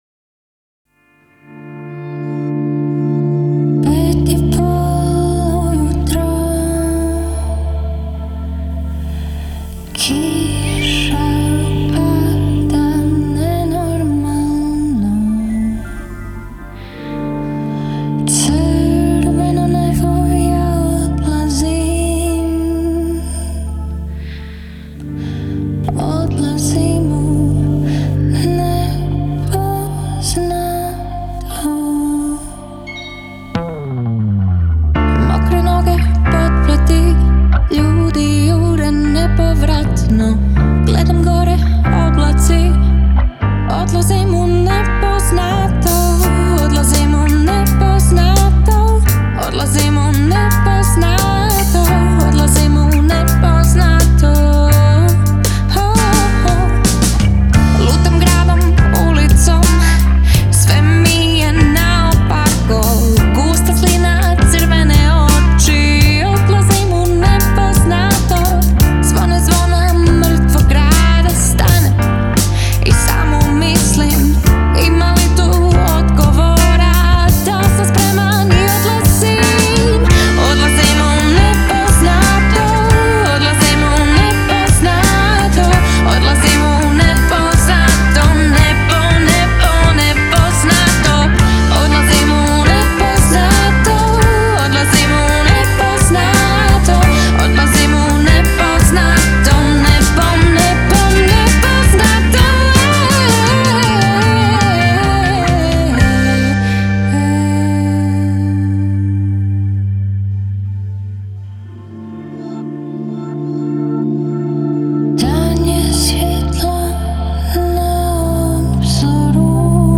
pop-rock-funk-punk